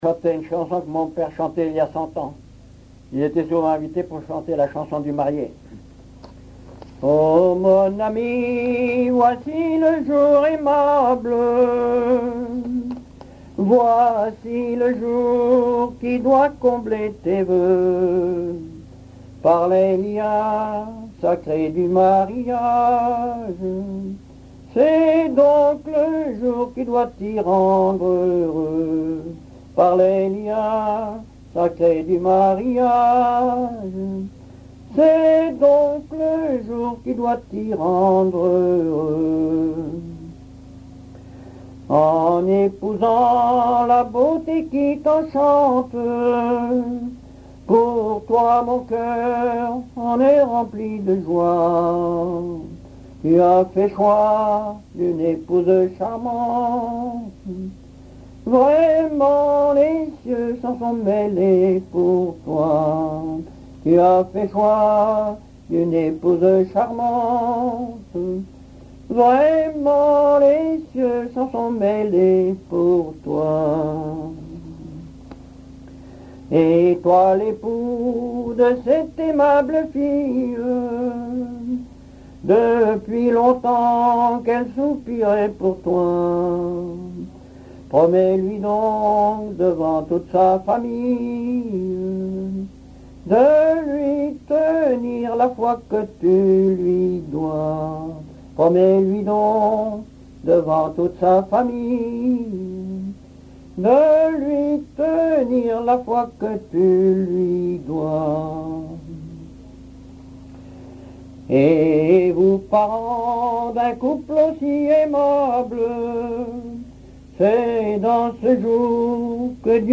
Mémoires et Patrimoines vivants - RaddO est une base de données d'archives iconographiques et sonores.
chansons populaires
Pièce musicale inédite